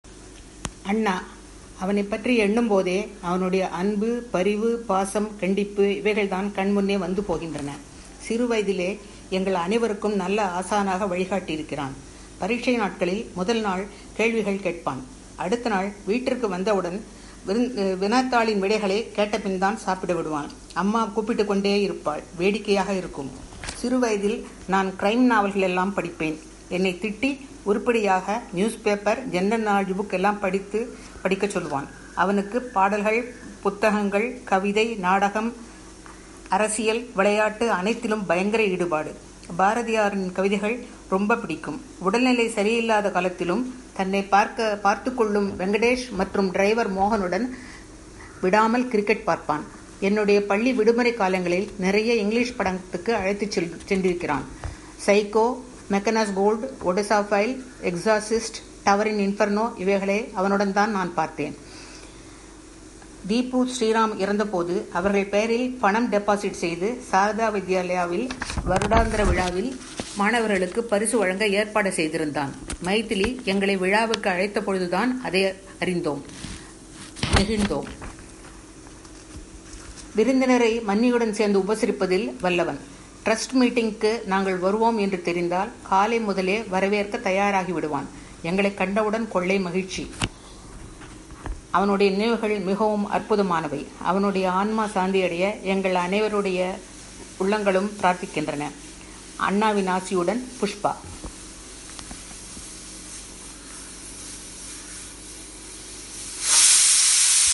If you cannot read Tamil but can understand Tamil, you can click here to listen to the audio of this article in author's voice